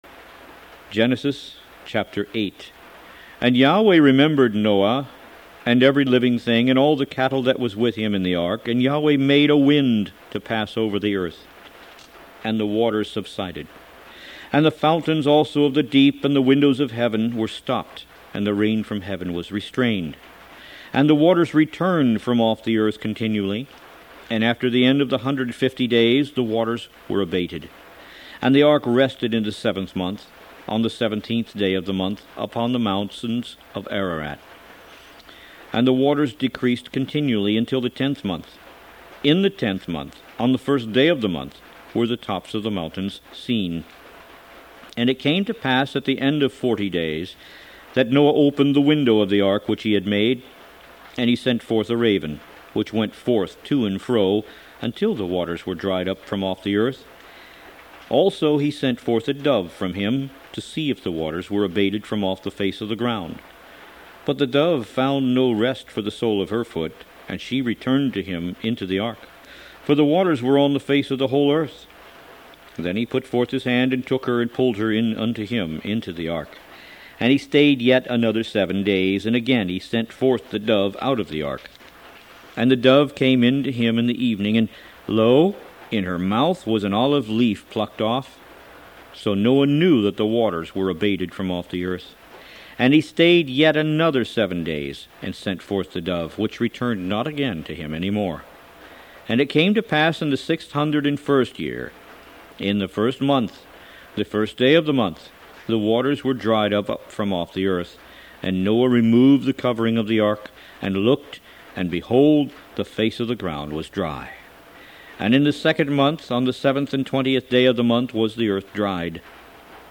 Root > BOOKS > Biblical (Books) > Audio Bibles > Tanakh - Jewish Bible - Audiobook > 01 Genesis